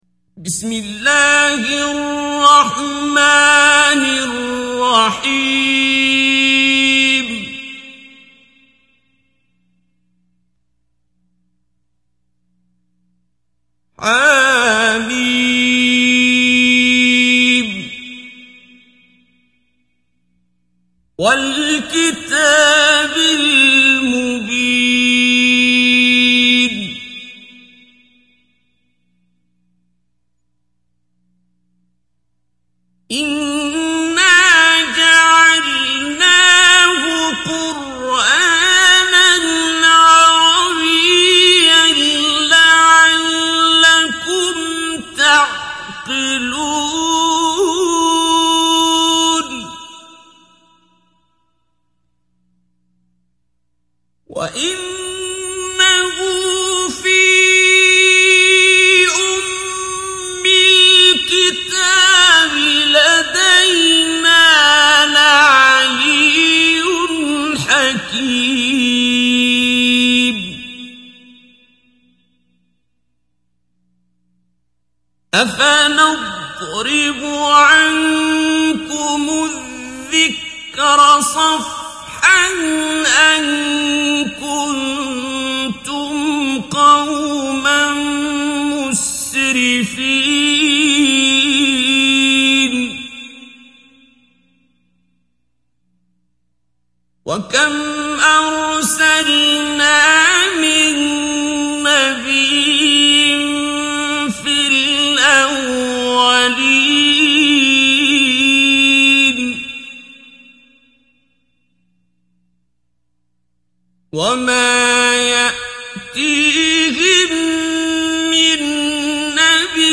Қуръони карим тиловати, Қорилар. Суралар Qur’oni karim tilovati, Qorilar.